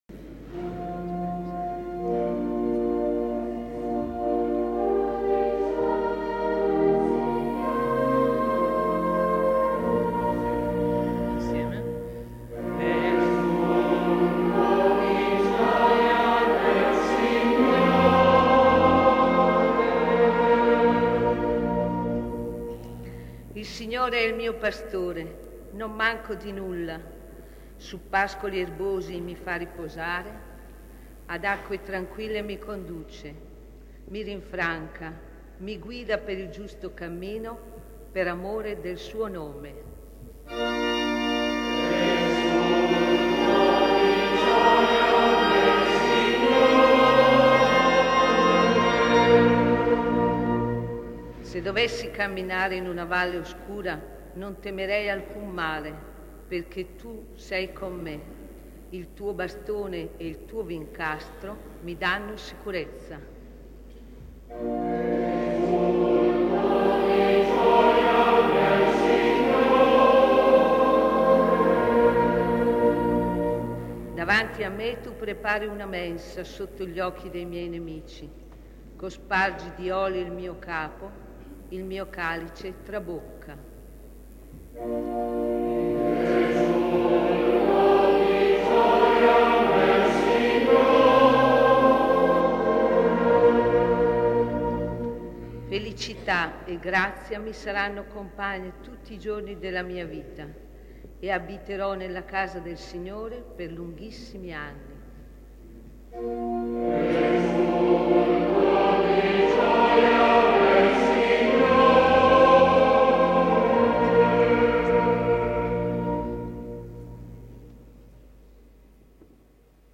Messa Solenne
S. Alessandro in Colonna